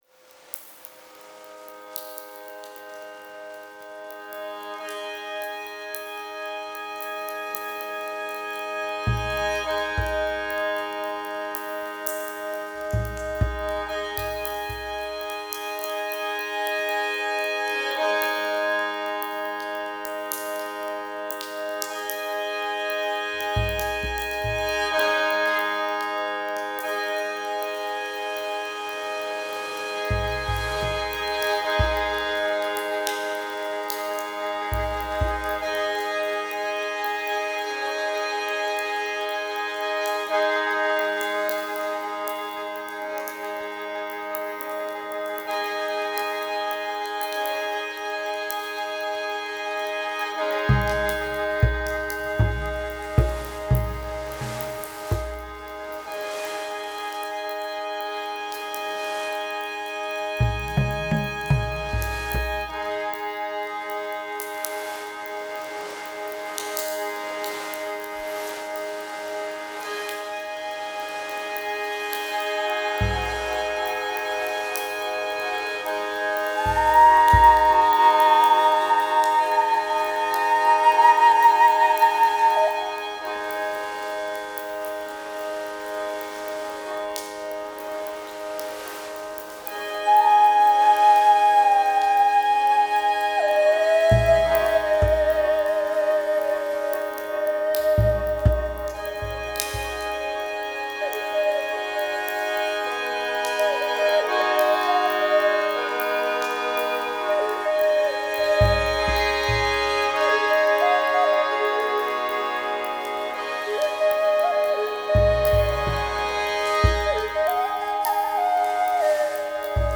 Genre: World Music
Recording: Windwood Studios